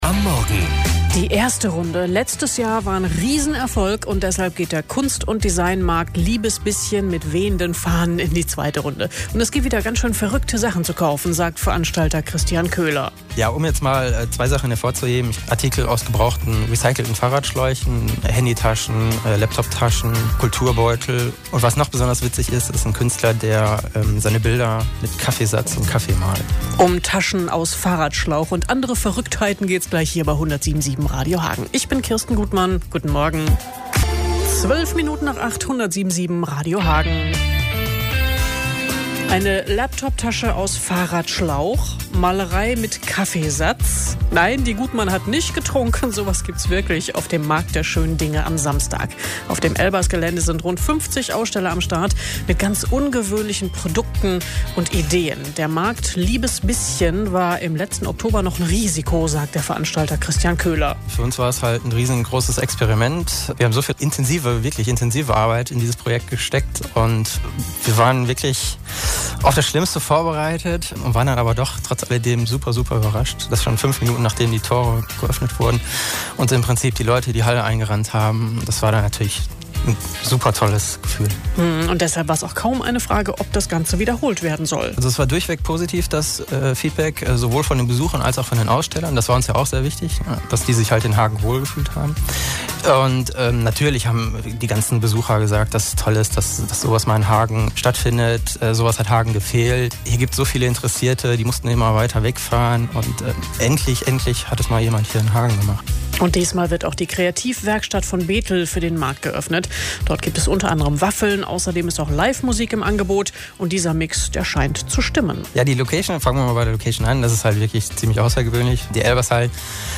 Hier könnt ihr den Bericht hören: liebesbisschen_RadioHagen
liebesbisschen_maennlich.mp3